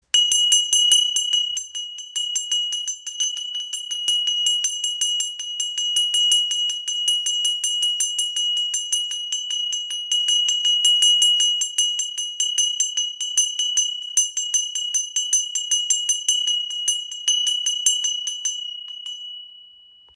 • Icon Reiner Klang – Ideal für Meditation und spirituelle Rituale
Tempelglocke mit Ganesha – Traditionelles Ritualinstrument
Diese Tempelglocke mit Ganesha erzeugt einen klaren Klang und symbolisiert Weisheit sowie das Überwinden von Hindernissen.
• Material: Messing
• Höhe: 17 cm, ø 9 cm